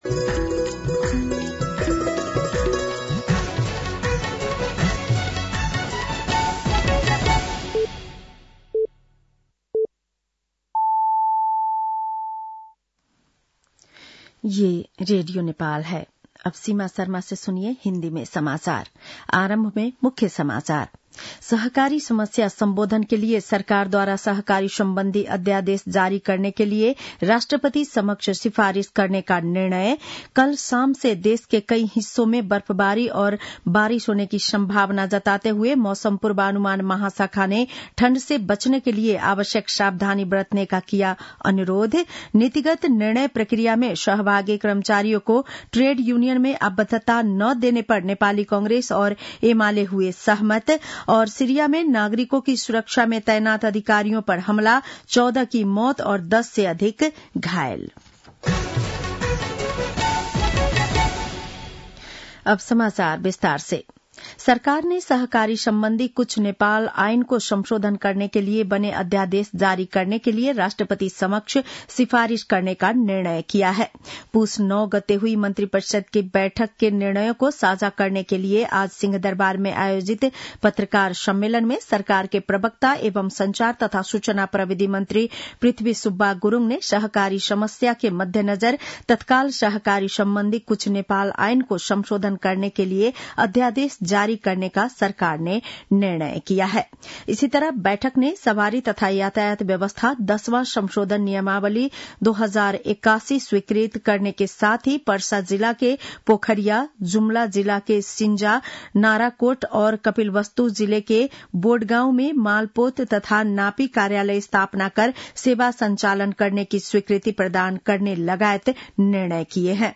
बेलुकी १० बजेको हिन्दी समाचार : १३ पुष , २०८१